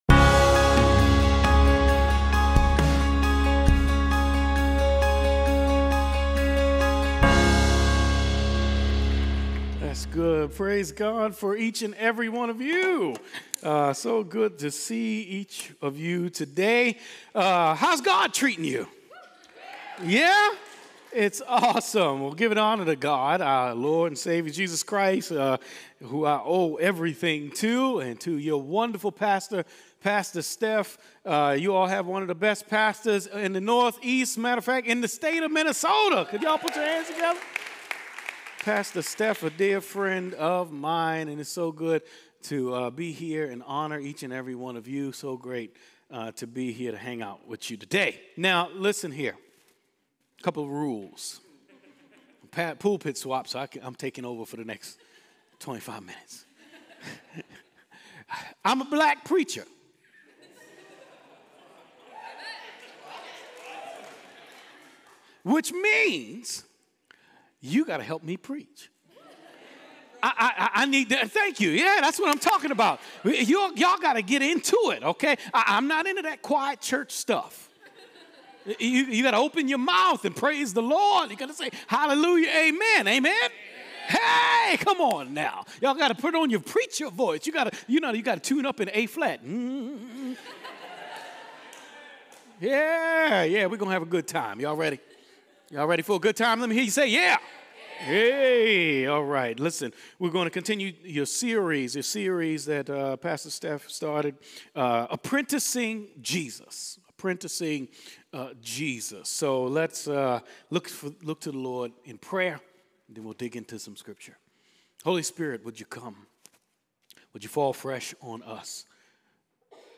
Preached